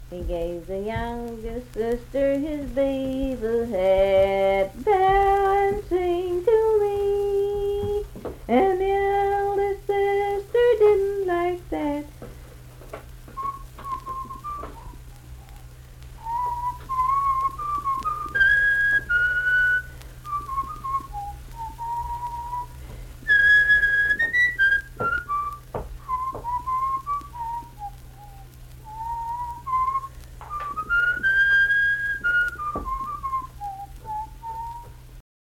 Unaccompanied vocal music
Verse-refrain 1(6).
Voice (sung)
Nicholas County (W. Va.), Richwood (W. Va.)